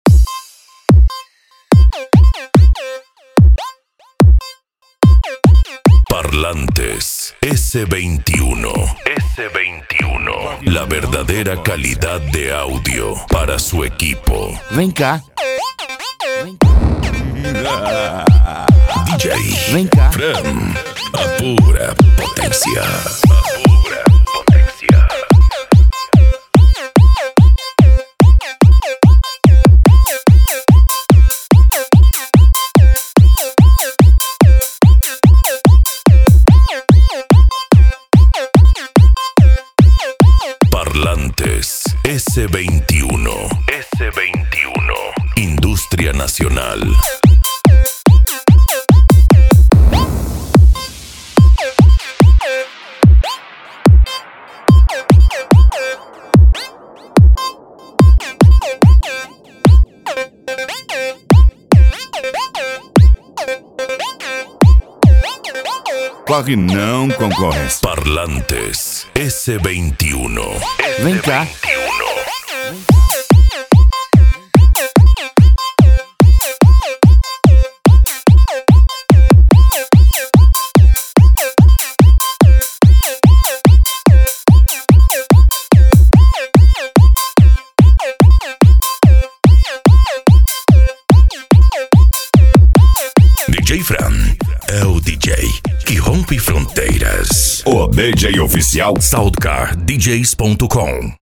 Eletronica
PANCADÃO
Psy Trance
Remix